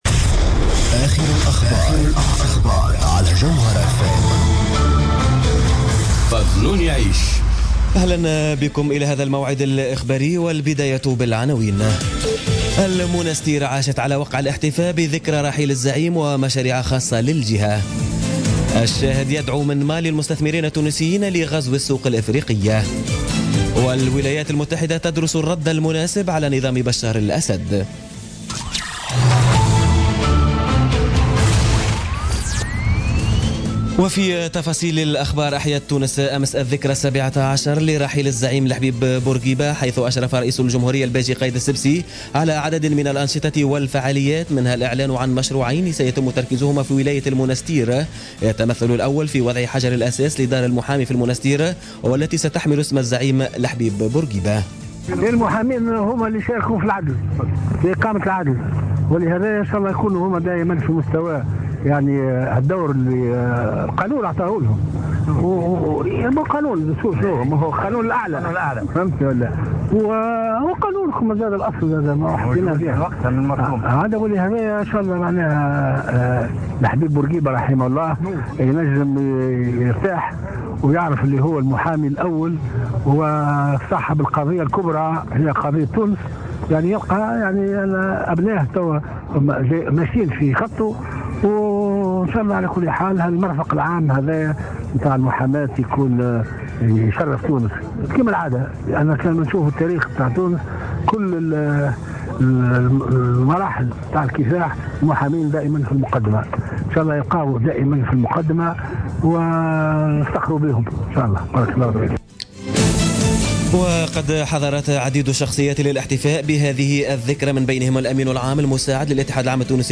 نشرة أخبار منتصف الليل ليوم الجمعة 7 أفريل 2017